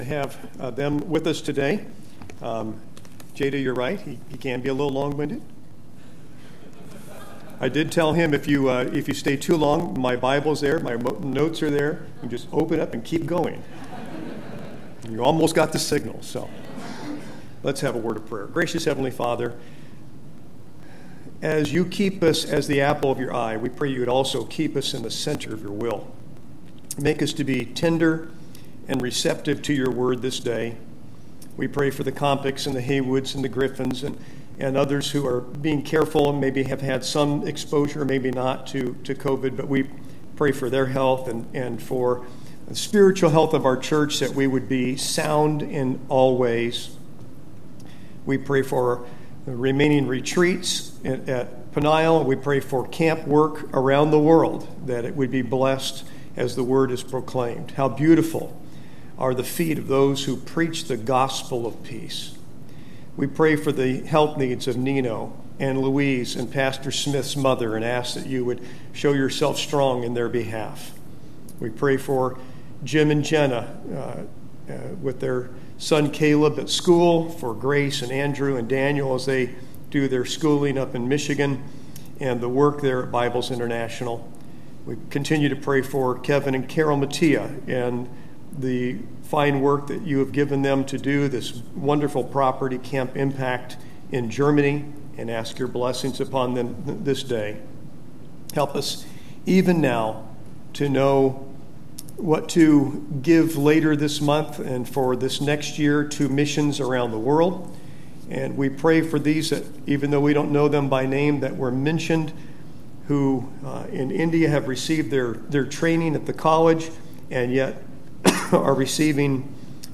ServiceMissionary ConferenceSunday Morning